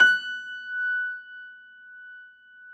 53h-pno20-F4.aif